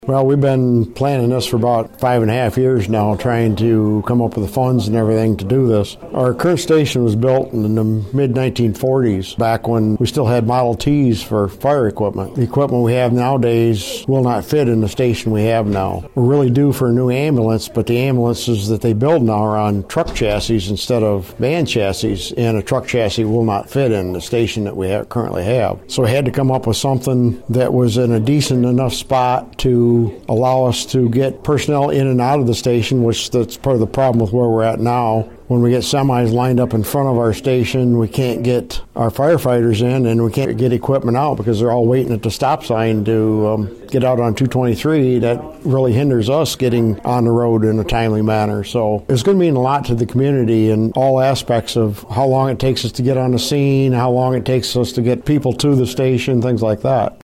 Palmyra Township Supervisor Dave Pixley.